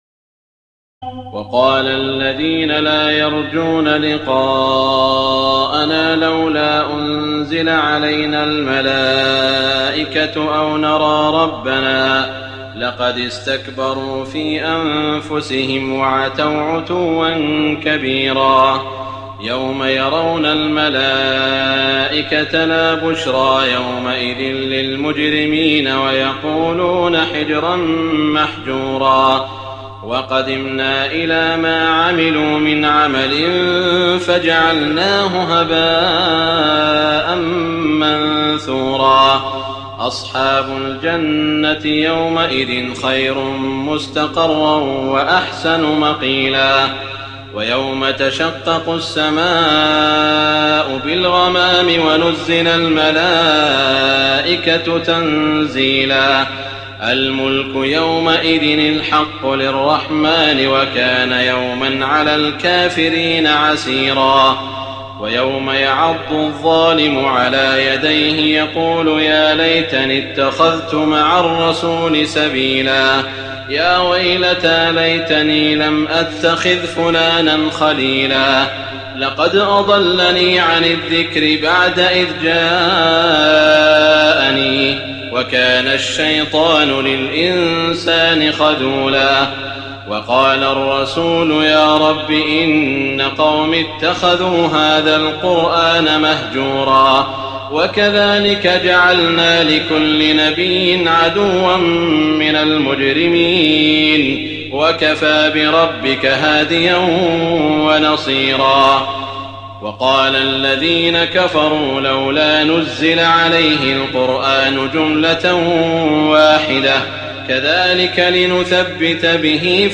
تراويح الليلة الثامنة عشر رمضان 1419هـ من سورتي الفرقان (21-77) و الشعراء (1-104) Taraweeh 18 st night Ramadan 1419H from Surah Al-Furqaan and Ash-Shu'araa > تراويح الحرم المكي عام 1419 🕋 > التراويح - تلاوات الحرمين